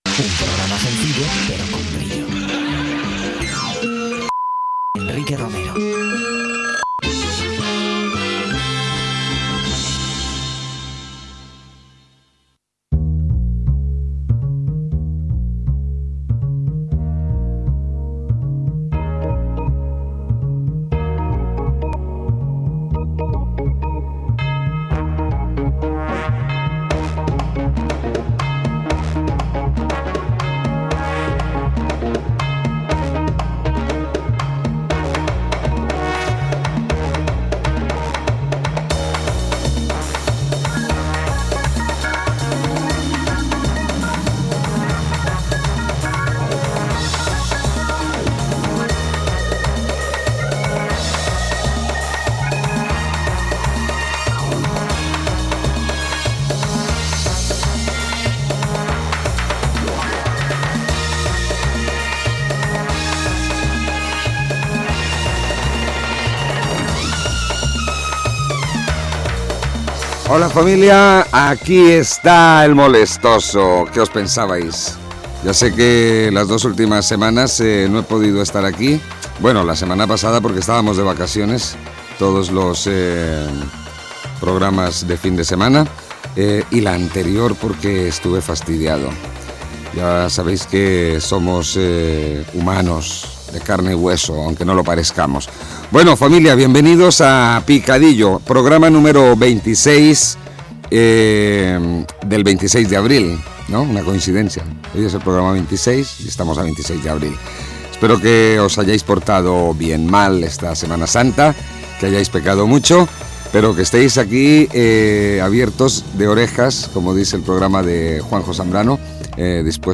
Careta del programa, presentació del programa número 26, publicitat i tema musical
Musical
FM